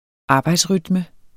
Udtale [ ˈɑːbɑjds- ]